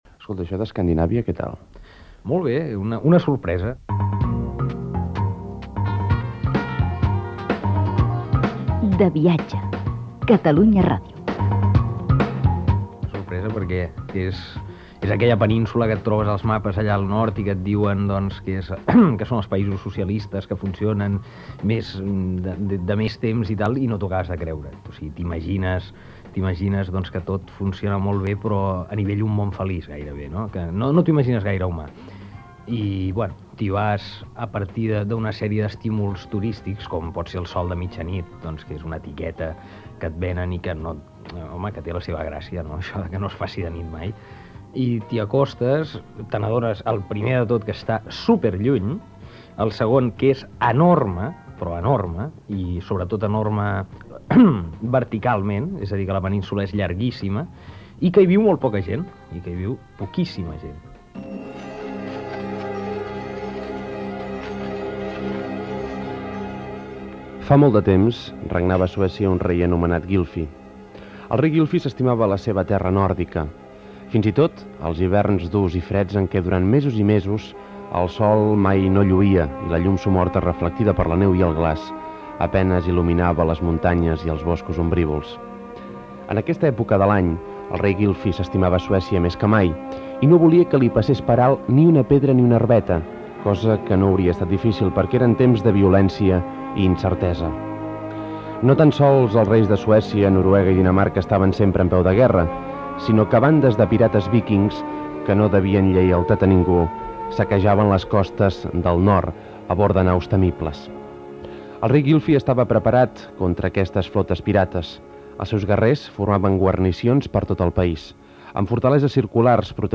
Presentació del programa dedicat als països nòrdics i entrevista a l'escriptor Marius Serra sobre un viatjge a Suècia que havia fet uns anys ençà
Divulgació
FM